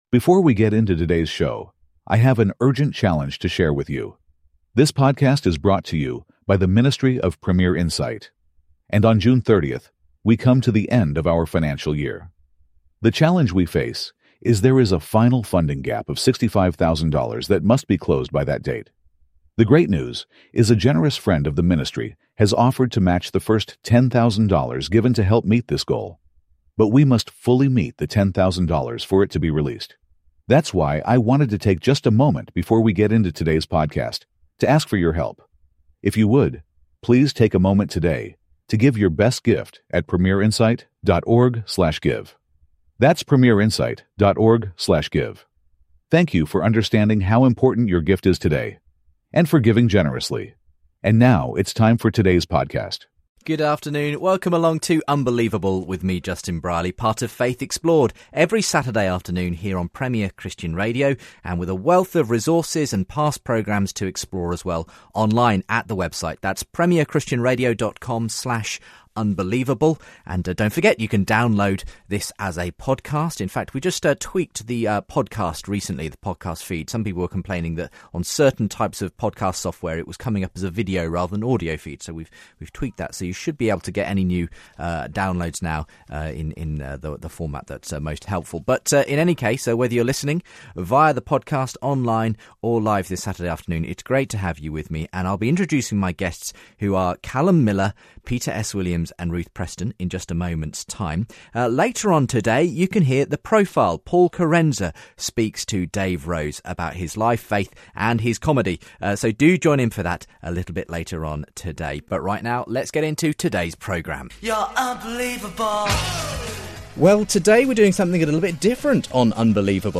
Grill an apologist – members of UK Apologetics respond to sceptic callers